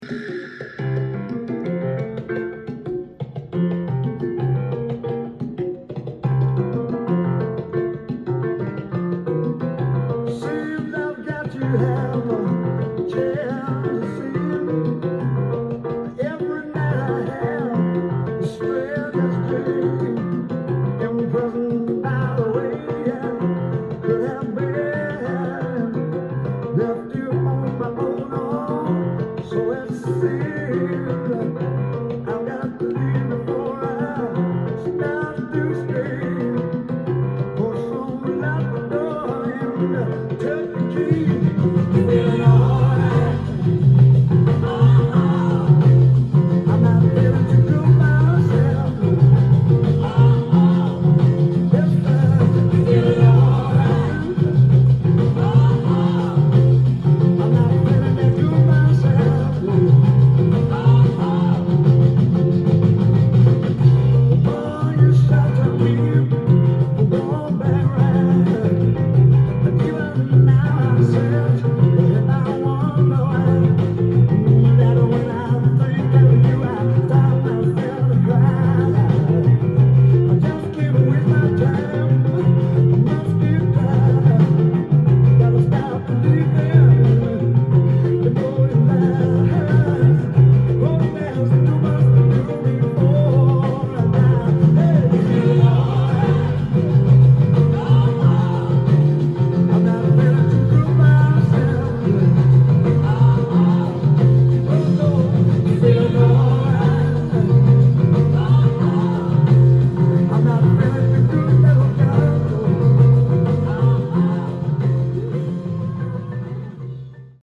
ジャンル：ROCK & POPS
店頭で録音した音源の為、多少の外部音や音質の悪さはございますが、サンプルとしてご視聴ください。
Backing Vocals
Bass
Congas [Tumba], Maracas
Drums
Piano